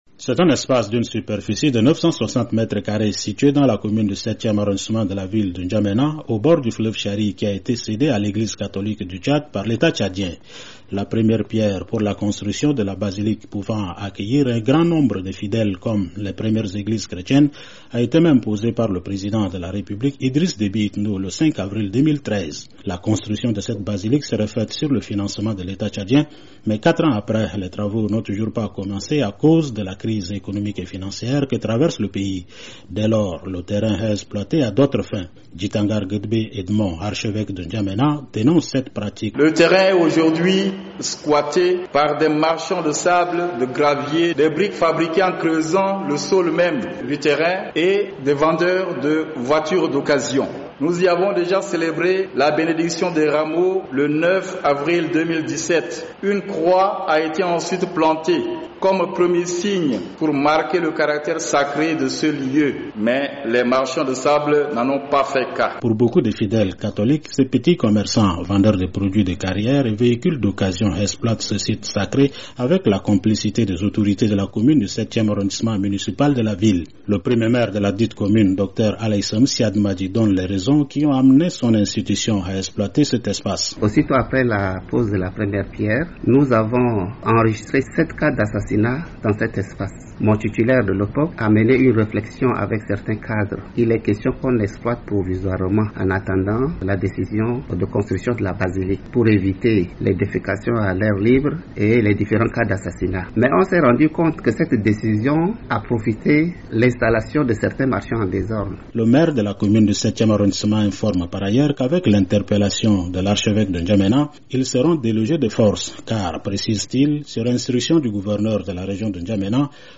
Brèves Sonores